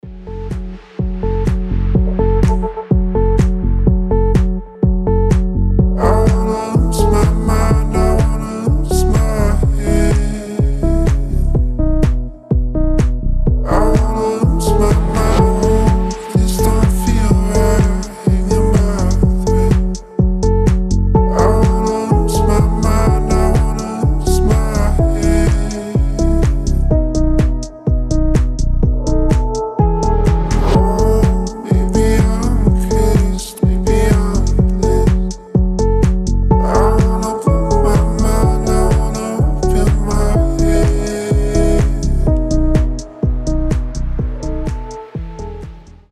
• Качество: 320, Stereo
deep house
атмосферные
красивый мужской голос
спокойные
chillout
расслабляющие
Стиль: chill house, deep house